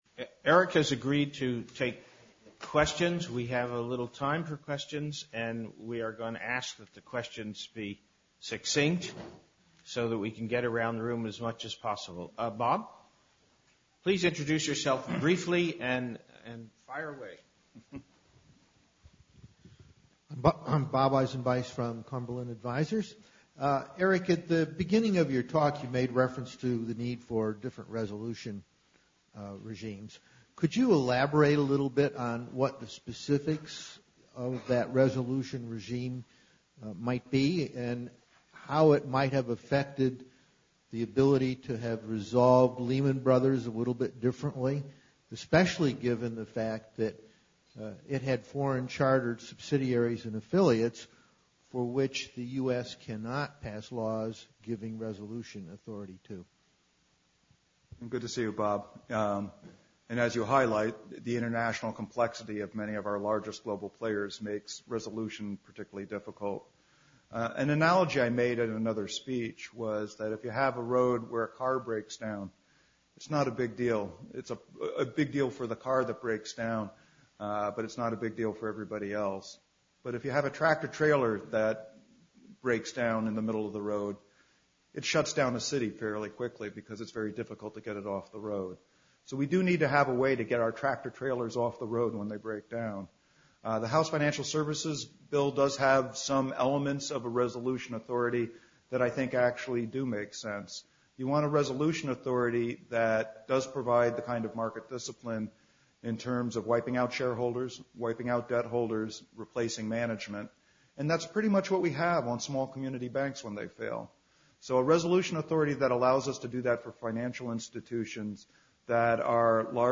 Full Text Speech